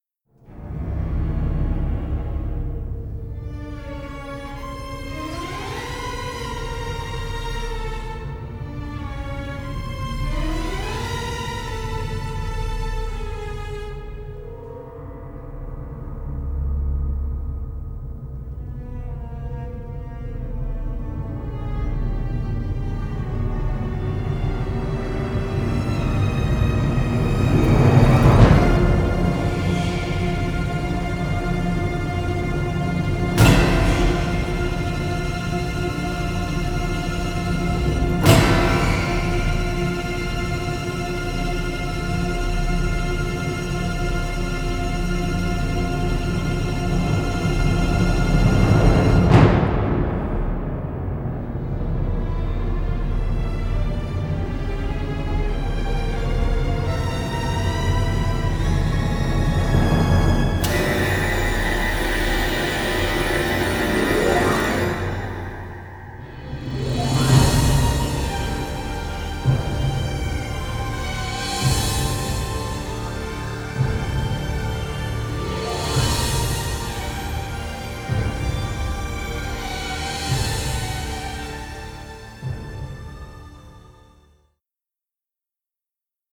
dark thriller score
original stereo session mixes